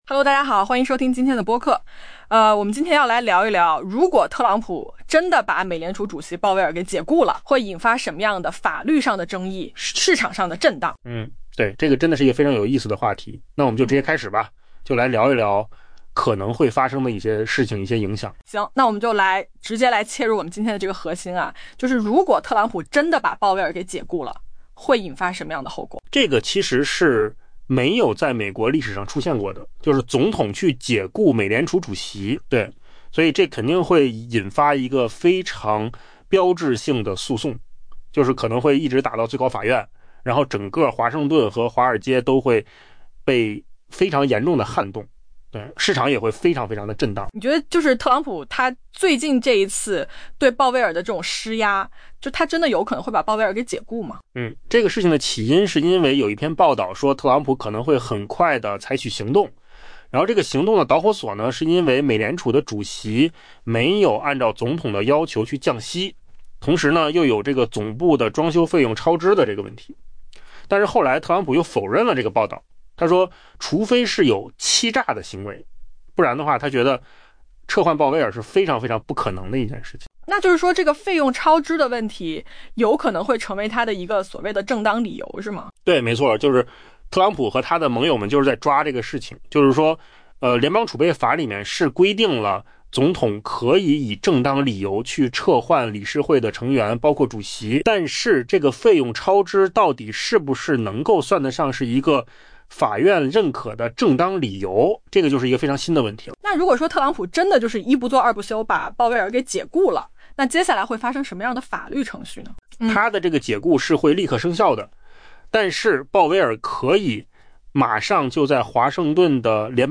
AI播客：换个方式听新闻 下载mp3 音频由扣子空间生成 特朗普对鲍威尔持续施压，再次搅动市场，并引发一个关键问题： 若这位总统真的解雇身处困境的美联储主席，后续将发生什么？